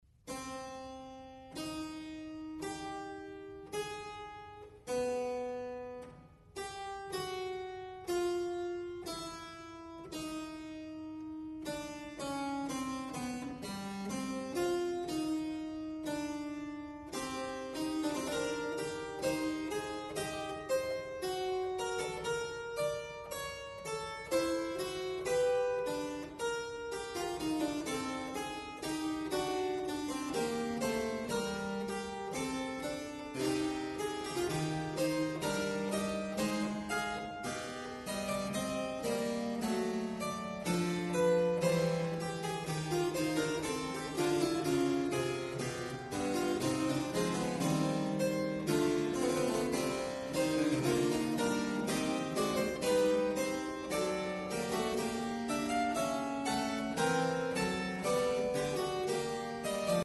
Great Classical Music